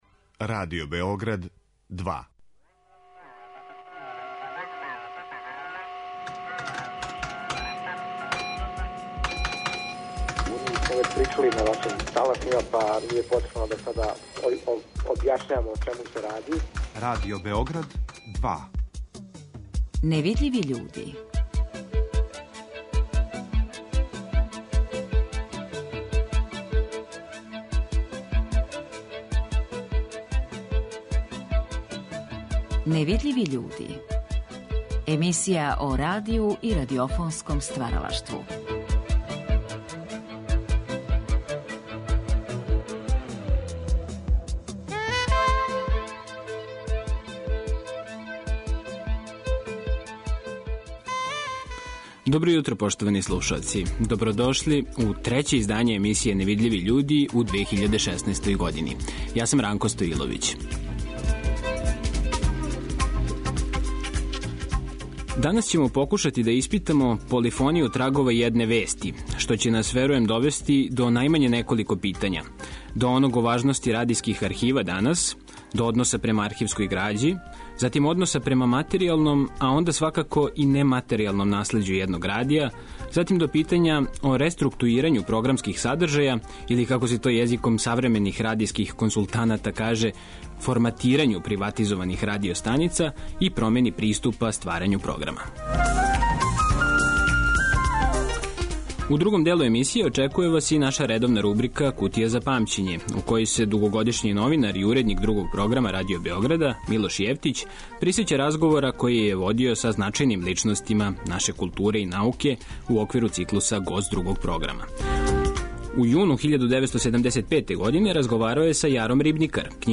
Двоје атрактивних гостију уживо у Студију 2 Радио Београда говоре о овим темама.